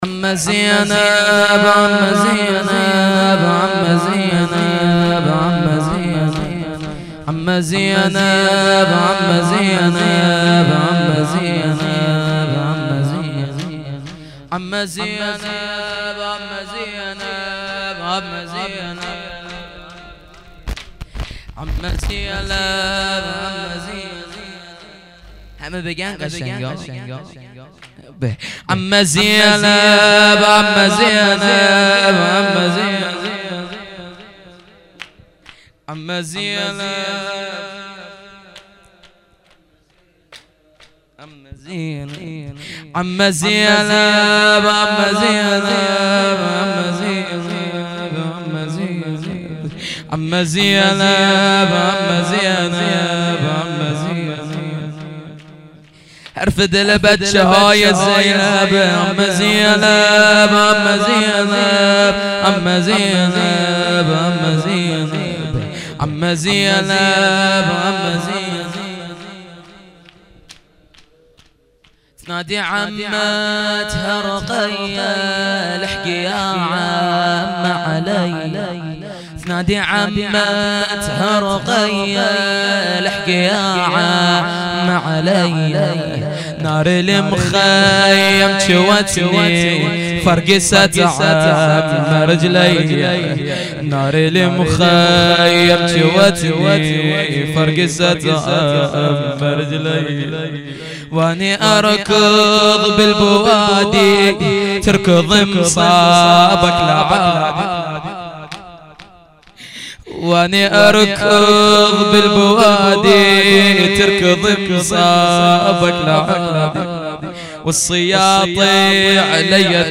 shahadat-hazrat-khadije-93-shor-arabi.mp3